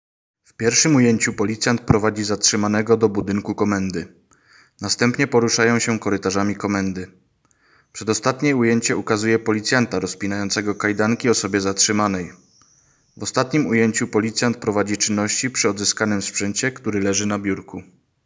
Nagranie audio Audiodeskrypcja_sprzet.m4a